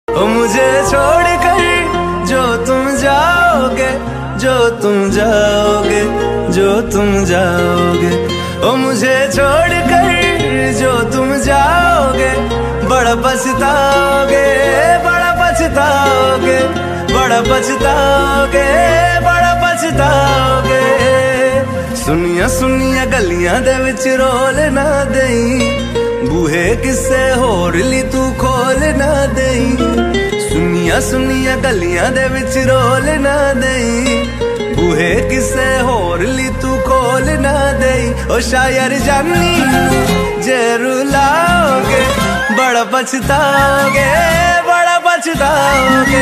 Category Bollywood